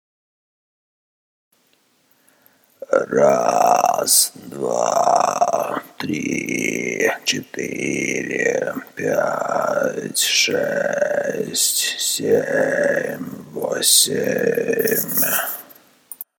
Как добиться такого эффекта речи (хруст)
Вкратце, ключевой фразой тут является уверенное владение приемом vocal fry.
Вот записал на микрофон ноута маленький кусочек для примера, специально сделал гипертрофированно. Никакой обработки, все делается одним голосом, ревера только чуток для удобоваримости.